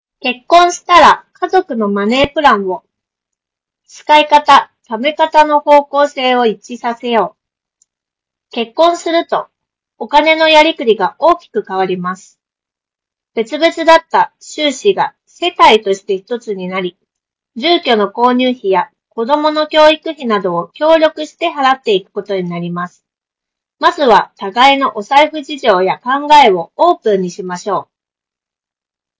上動画は、スマホで撮影した映像の音声を、1MORE Mini HQ20の内蔵マイクで収録した音声に置き換えたものだ。
さすがに専用のハイエンドマイクと比べると録音品質は劣るものの、普通に通話するには十分に優れた性能となっている。
▼1MORE Mini HQ20の内蔵マイクで収録した音声
発言内容を明瞭に拾い上げることができていることが分かる。
1more-sonoflow-mini-hq20-review.wav